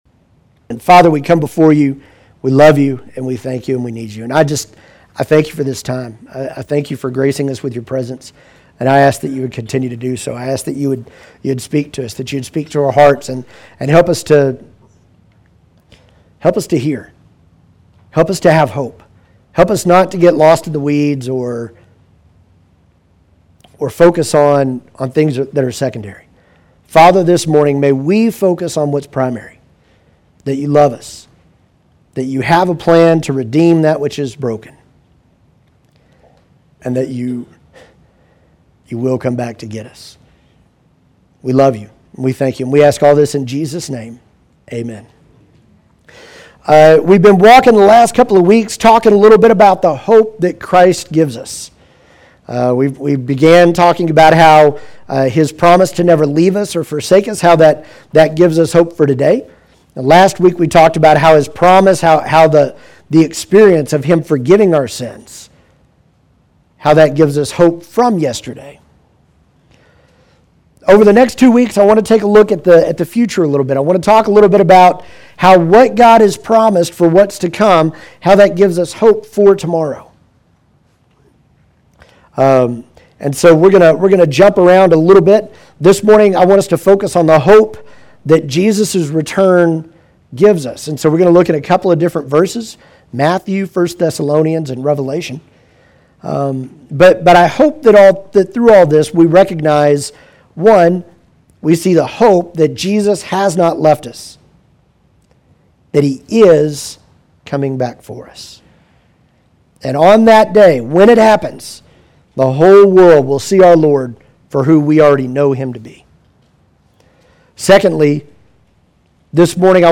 Chapel Hill Baptist Church Online Sermons